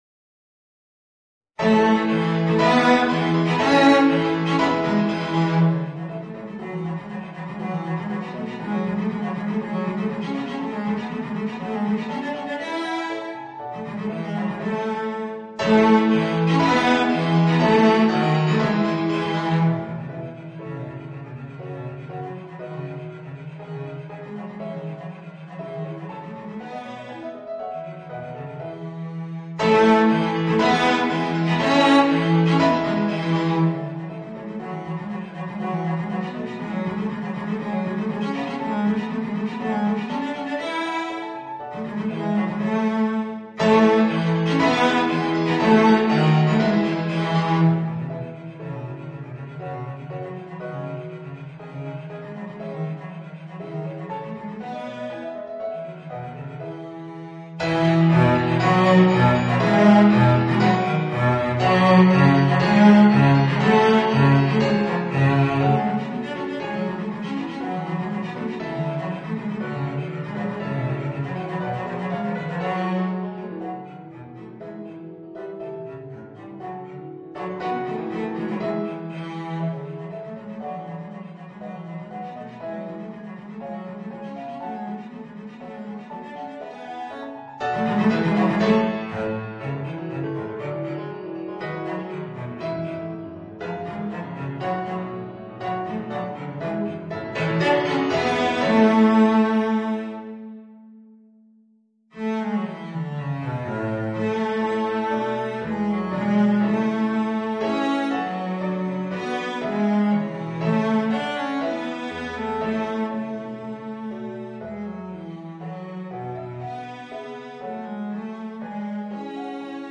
Voicing: Violoncello and Organ